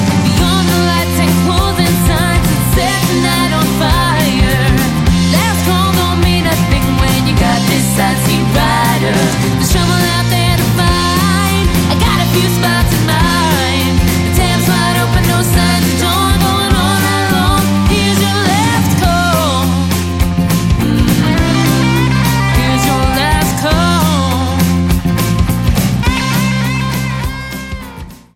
Eine sehr schöne Nummer, die das Tanzbein in Schwung bringt.